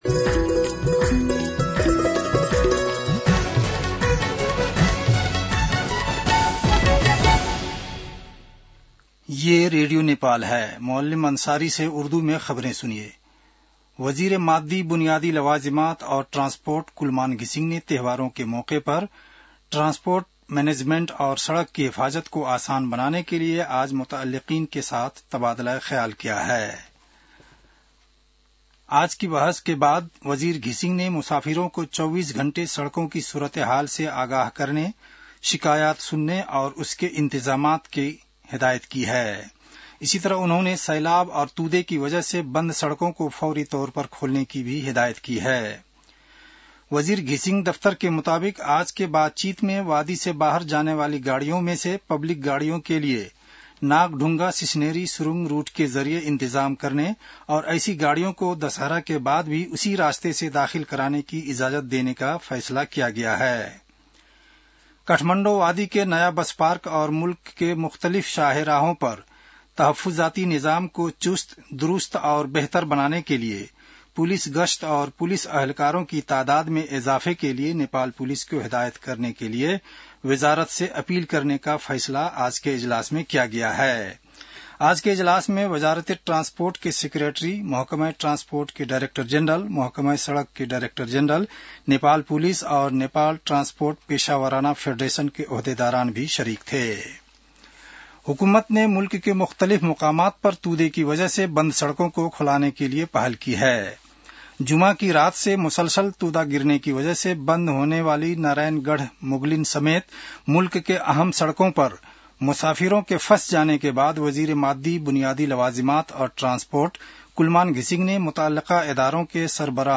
उर्दु भाषामा समाचार : ४ असोज , २०८२